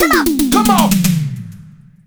117 BPM Beat Loops Download